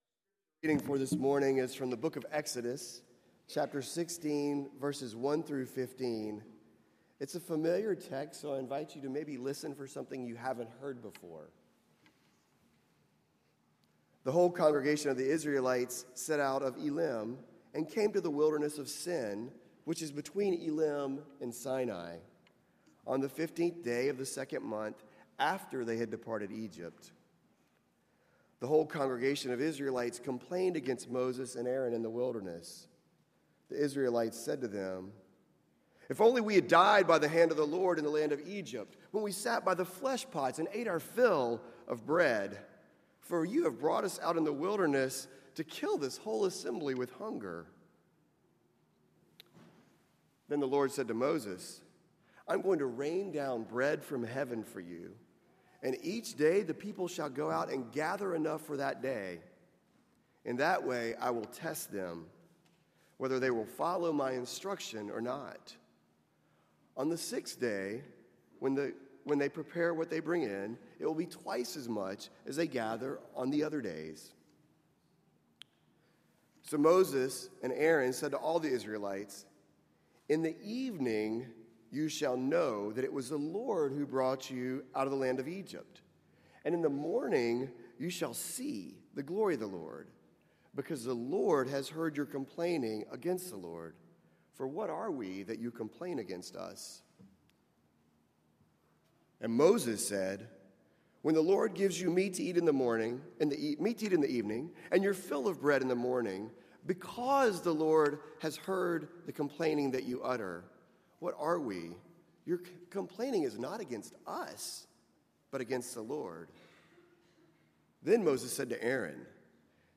NDPC Worship from 08/05/2018 Back to All Worships August 5